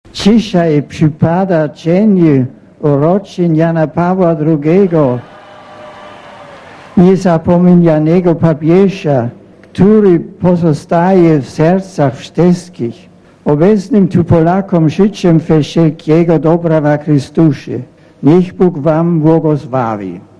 Podczas audiencji generalnej w Watykanie Benedykt XVI mówił po polsku i po włosku o przypadającej tego dnia 85. rocznicy urodzin Jana Pawła II.
Mówi papież Benedykt XVI